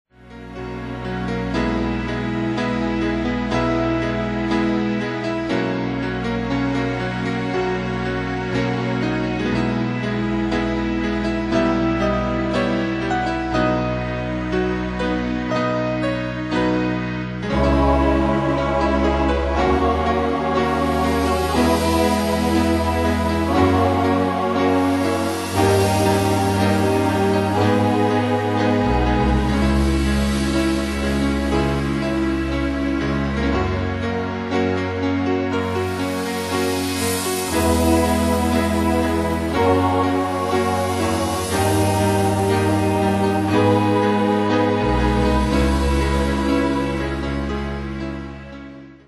Danse/Dance: Ballade Cat Id.
Pro Backing Tracks